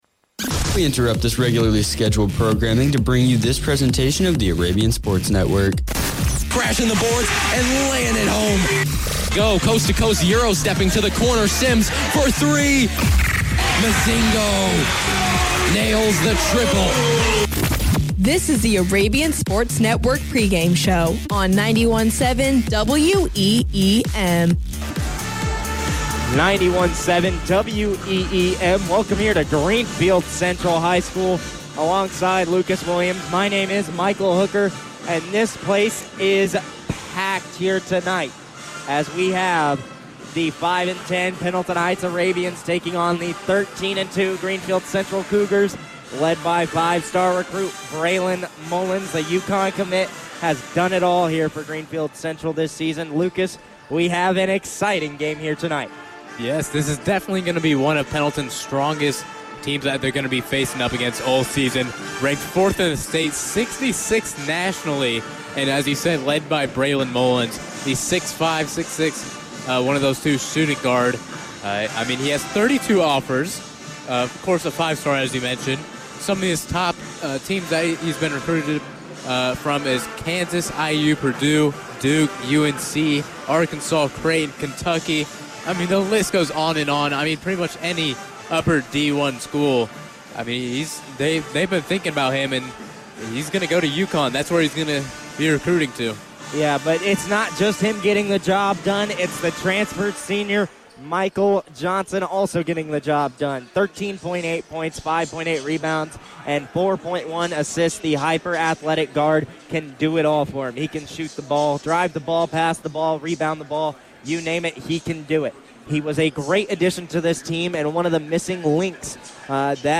Varsity Boys Basketball Broadcast Replay Pendleton Heights vs. Greenfield-Central 1-31-25